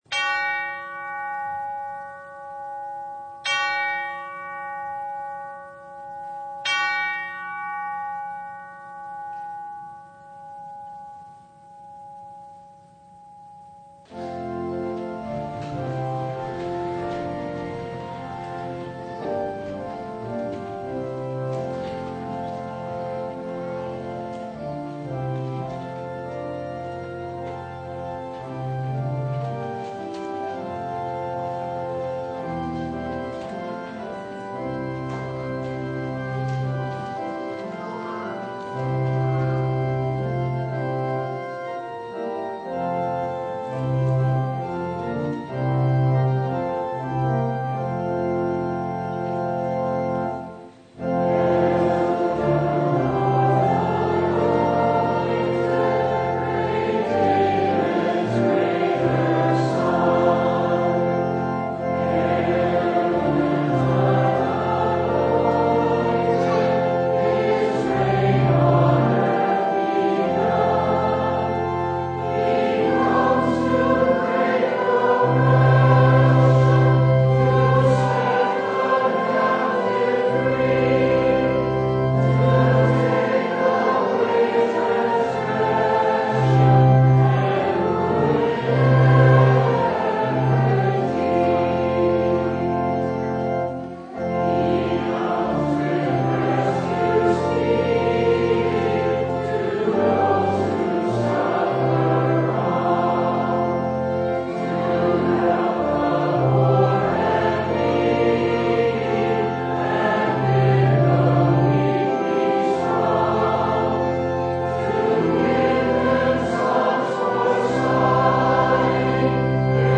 Service Type: Sunday
Topics: Full Service